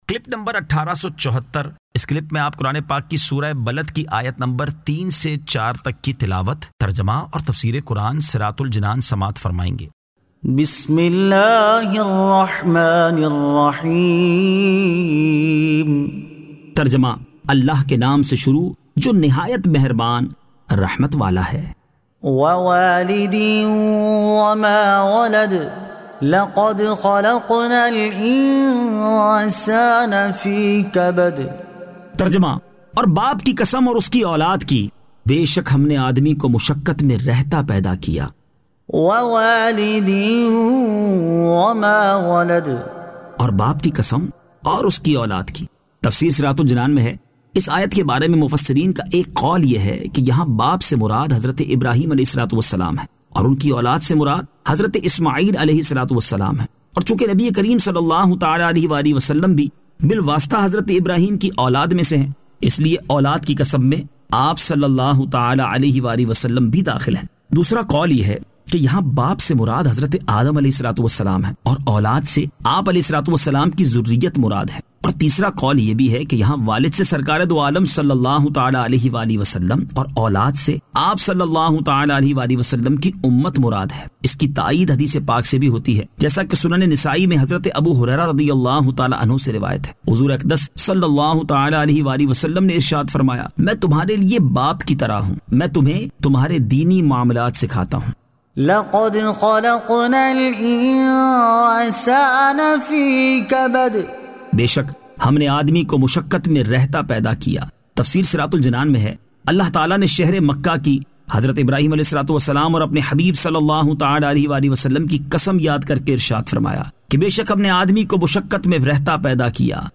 Surah Al-Balad 03 To 04 Tilawat , Tarjama , Tafseer